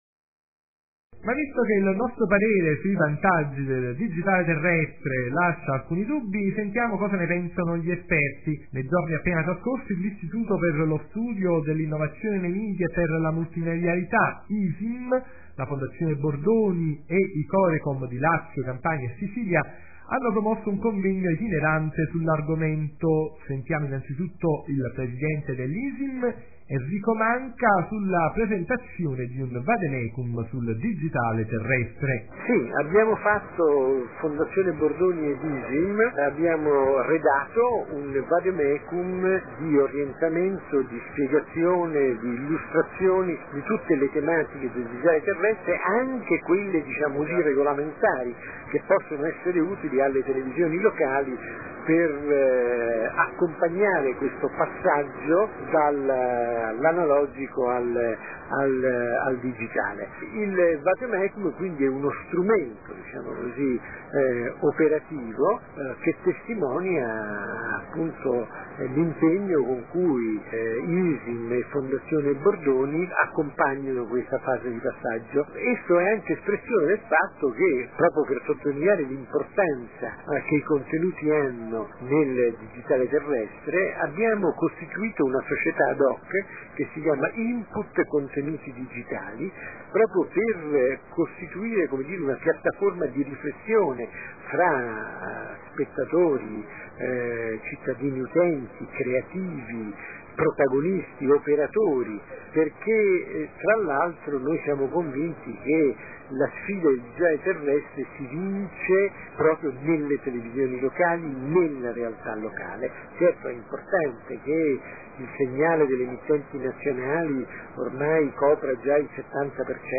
Oggi ci stiamo occupando del passaggio della televisione al digitale terrestre, con una serie di interviste raccolte nei giorni  scorsi ad u convegno itinerante promosso  dell’’Istituto per lo studio dell’Innovazione nei media e per la Multimedialità ISIMM, la Fondazione Bordoni e i Corecom di Lazio, Campania e Sicilia.